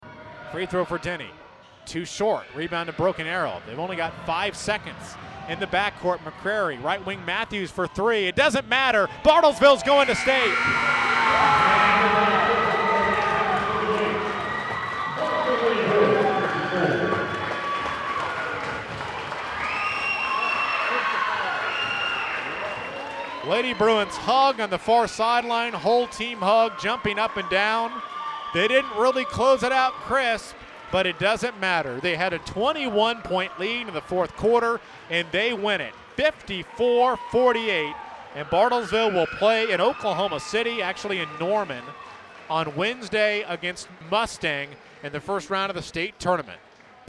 Here is how the final call sounded on Saturday evening on KWON.